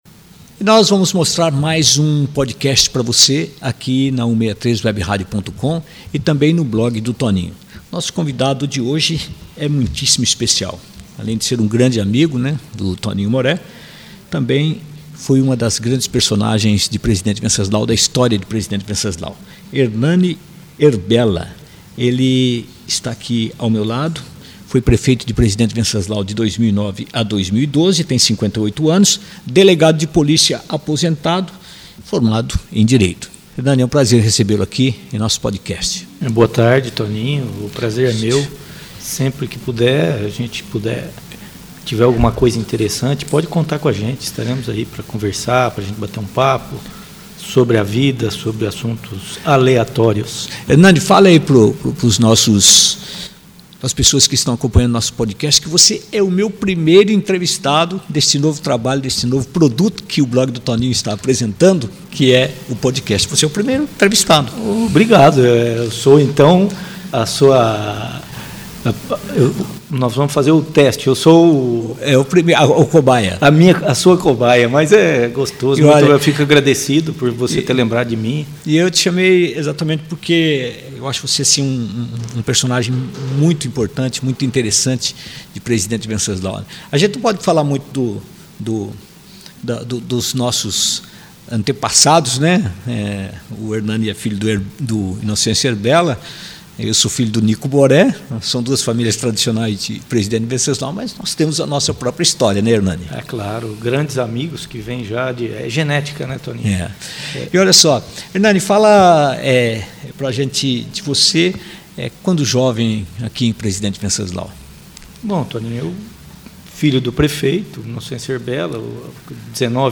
Podcast de entrevista como ex-prefeito Ernane Erbella